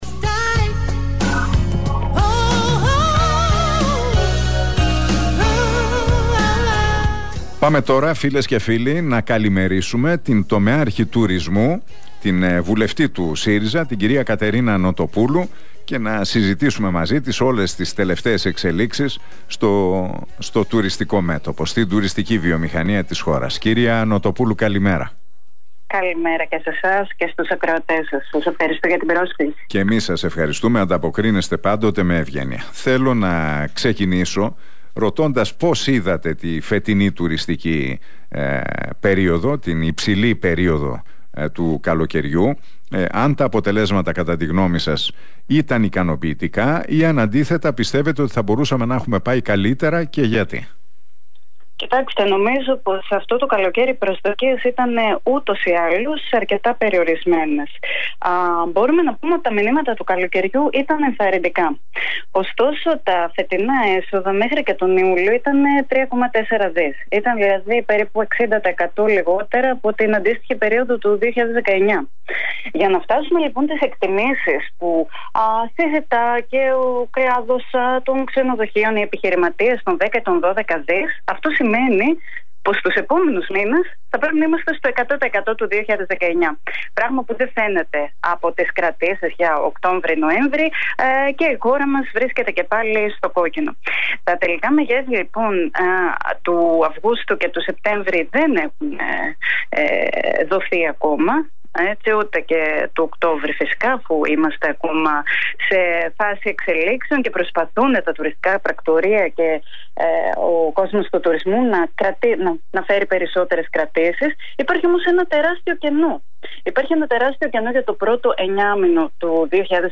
Η βουλευτής και τομεάρχης Τουρισμού του ΣΥΡΙΖΑ Κατερίνα Νοτοπούλου, μιλώντας στον Realfm 97,8 και στην εκπομπή του Νίκου Χατζηνικολάου δήλωσε ότι “αυτό το καλοκαίρι οι προσδοκίες ήταν ούτως ή άλλως αρκετά περιορισμένες.